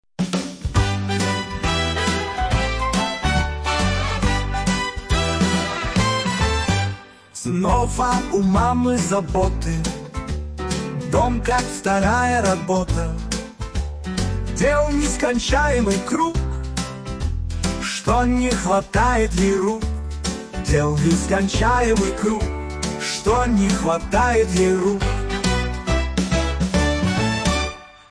Песня детская про робота для мамы
Фрагмент примера исполнения мужским голосом: